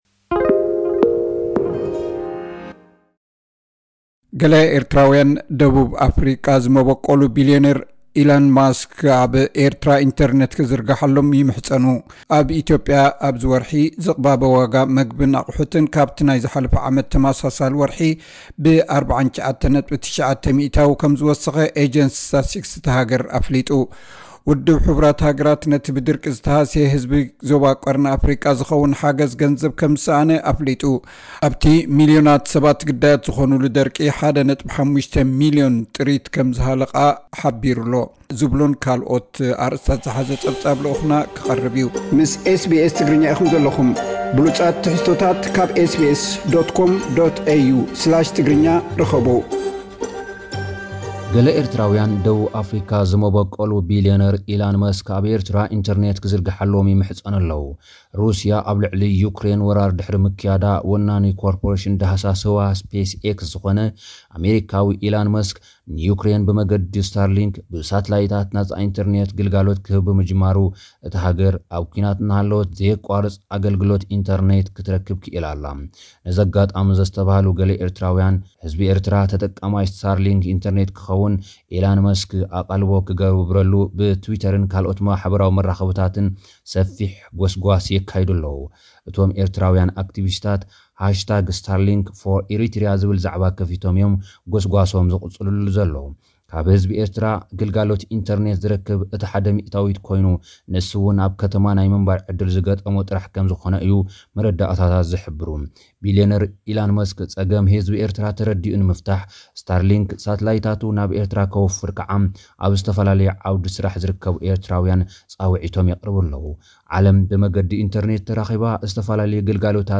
ውድብ ሕቡራት ሃገራት ነቲ ብደርቂ ዝተሃስየ ህዝቢ ዞባ ቀርኒ ኣፍሪቃ ዝኸውን ሓገዝ ገንዘብ ከም ዝሰኣነ ኣፍሊጡ። ኣብቲ ሚልዮናት ሰባት ግዳያት ዝኾኑሉ ደርቂ 1.5 ሚልዮን ጥሪት ከም ዝሃለቓሉ ሓቢሩ ኣሎ። ዝብሉ ካልእን ኣርእስታት ዝሓዘ ጸብጻብ ልኡኽና ክቐርብ እዩ።